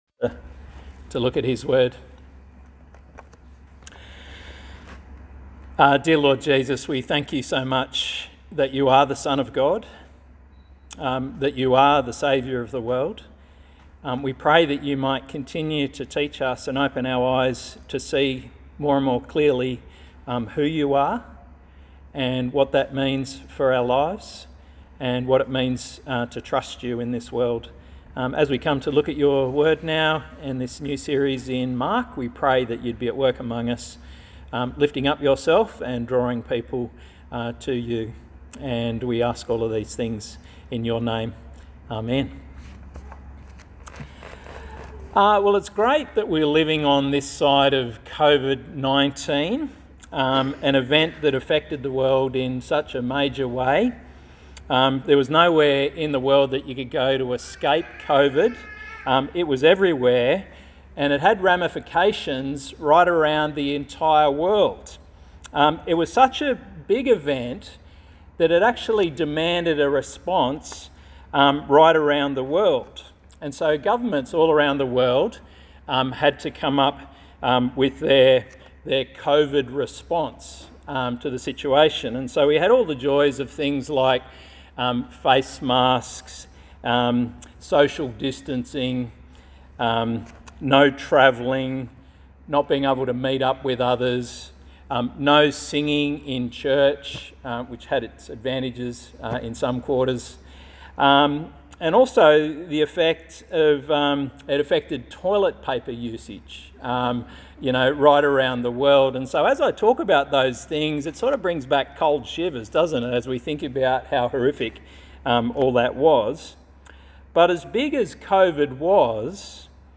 Passage: Mark 1:1-20 Service Type: Sunday Morning
sermon-20-oct-2.mp3